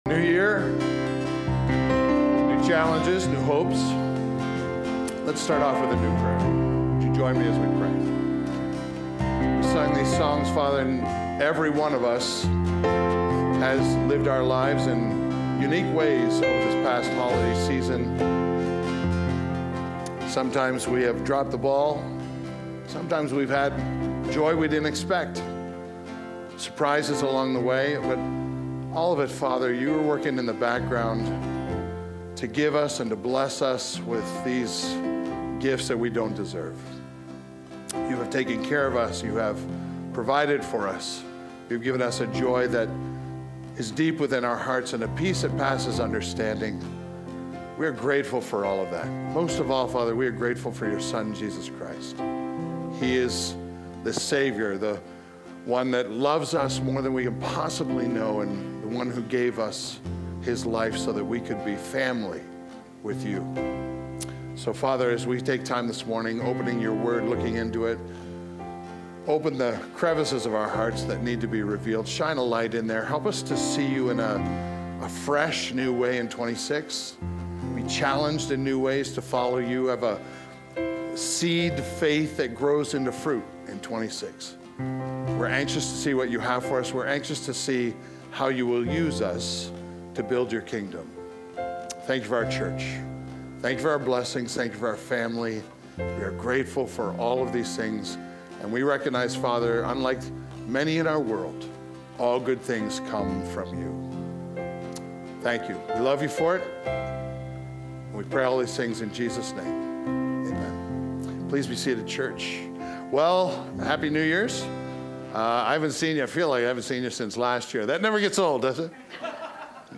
This sermon covers God's interactions with humankind and reminders of His kindness, mercy, faithfulness, and justice and how these attributes impact us throughout our lives.